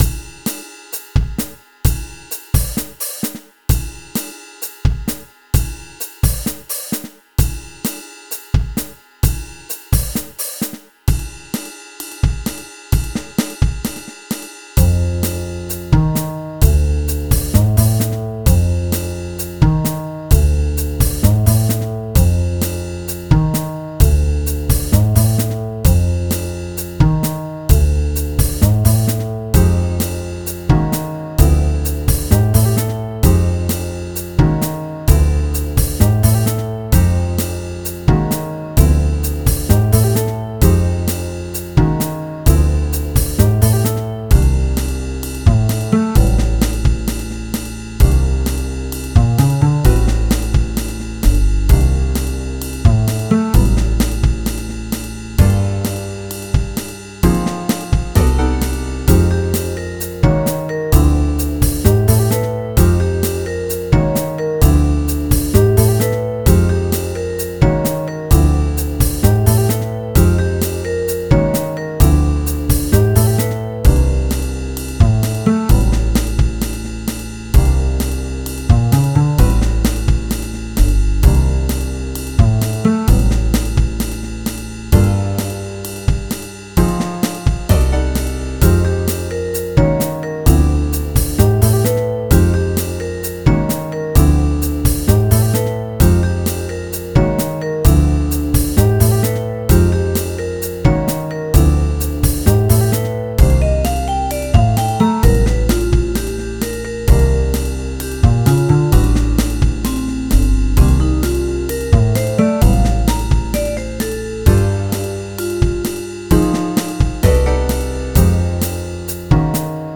Genre Jazz